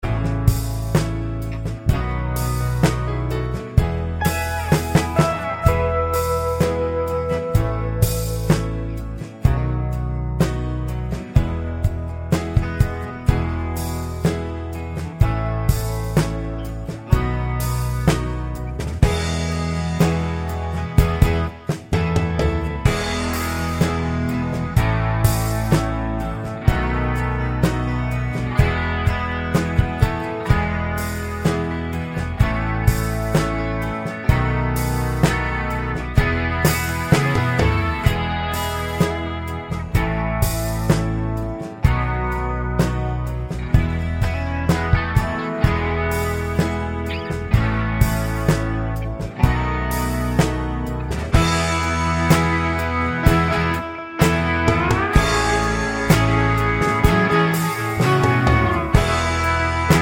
no Backing Vocals Soft Rock 6:41 Buy £1.50